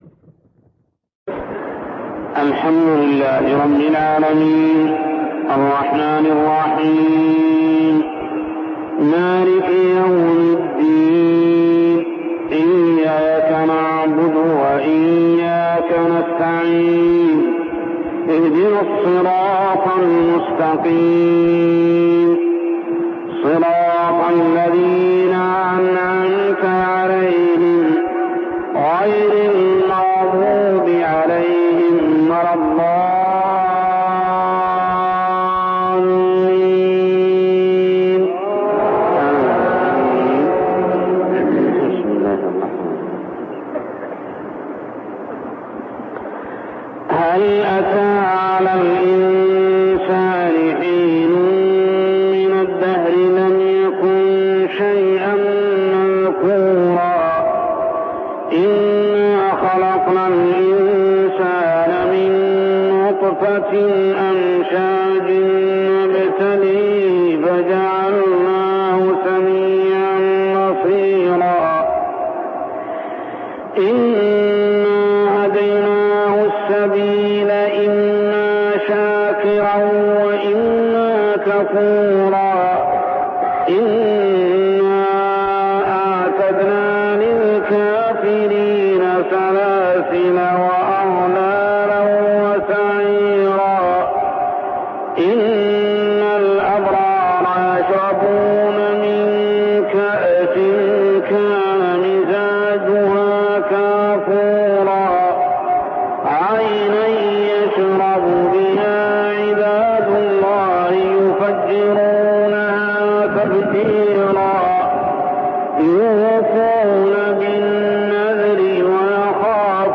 تلاوة من صلاة الفجر لسورة الإنسان كاملة عام 1399هـ | Fajr prayer Surah Al-Insan > 1399 🕋 > الفروض - تلاوات الحرمين